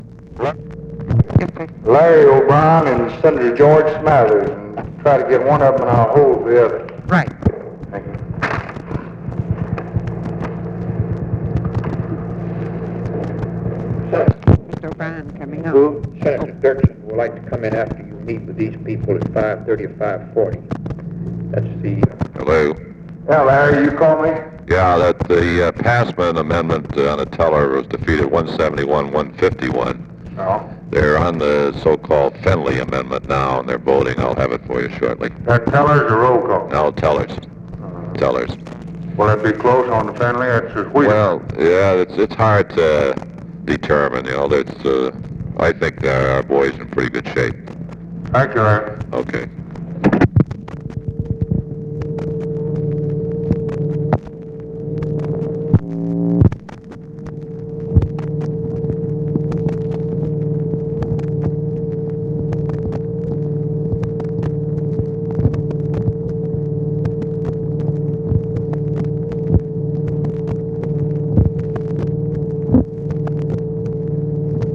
Conversation with LARRY O'BRIEN and OFFICE CONVERSATION, July 1, 1964
Secret White House Tapes